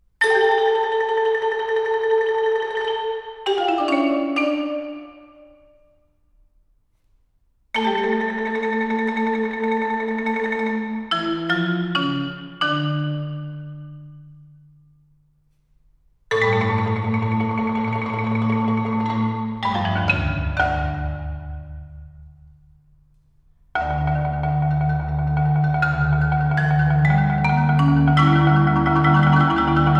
Marimba and Vibraphone